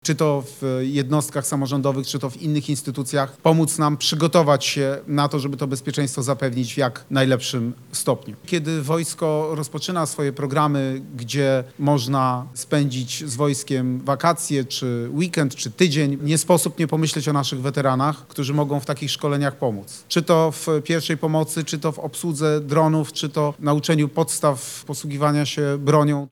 Wykorzystanie potencjału wojskowych weteranów zapowiedział w Dęblinie kandydat na prezydenta Polski Rafał Trzaskowski. Chodzi o przygotowywaną koncepcję programu Centrum Drugiej Misji, gdzie wiedza weteranów byłaby wykorzystana do wzmocnienia bezpieczeństwa kraju.